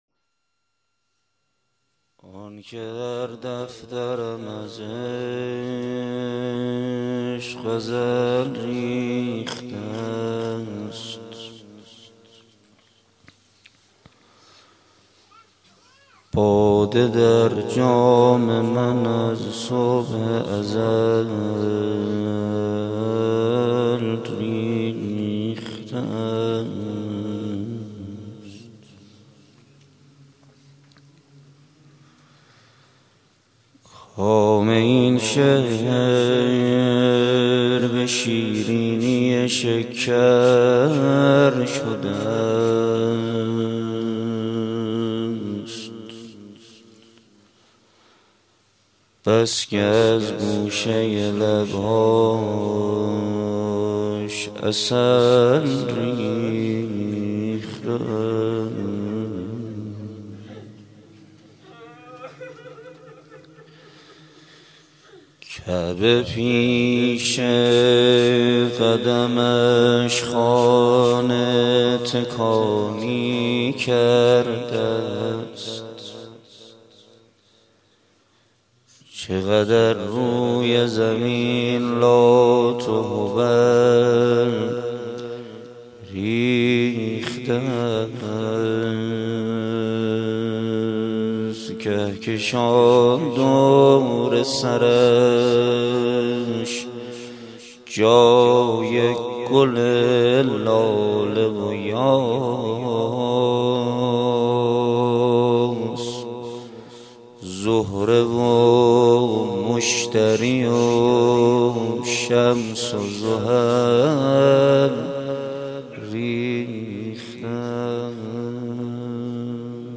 جلسه ی هفتگی 17 شهریور 1394 | هیات ریحانه النبی | کربلایی محمدحسین پویانفر